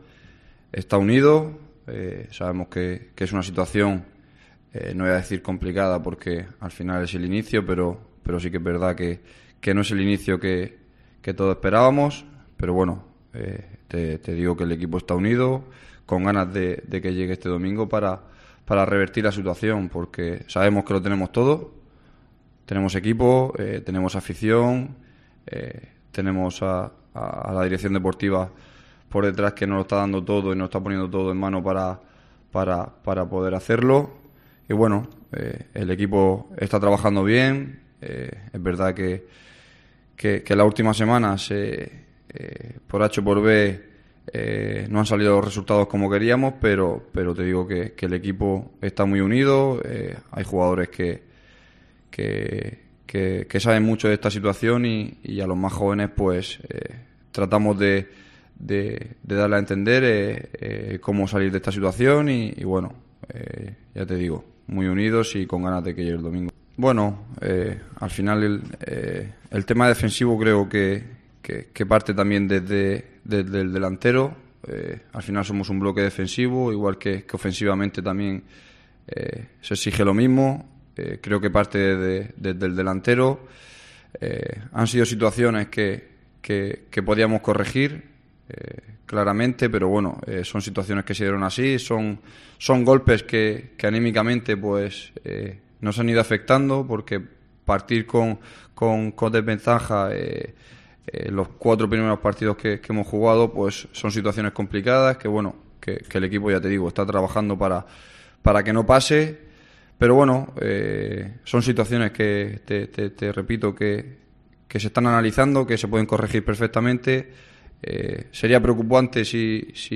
en la sala de prensa del estadio Enrique Roca de Murcia
comparecencia de prensa